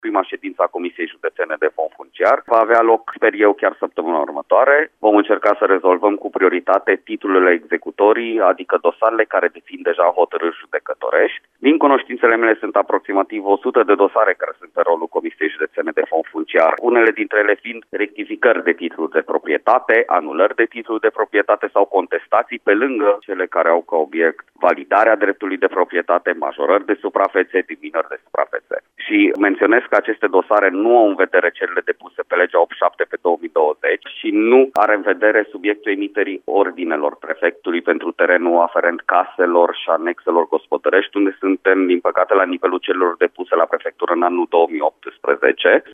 Anunțul a fost făcut la Radio Timișoara de prefectul Zoltan Nemeth.